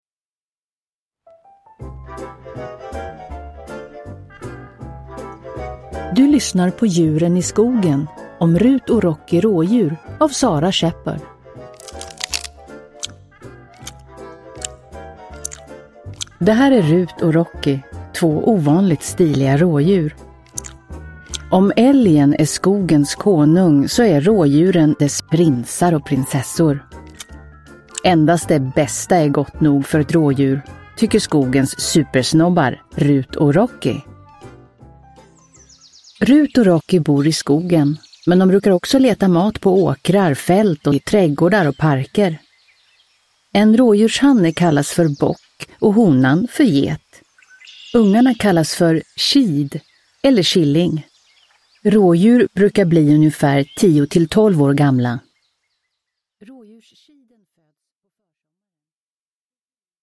Rut och Rocky Rådjur – Ljudbok – Laddas ner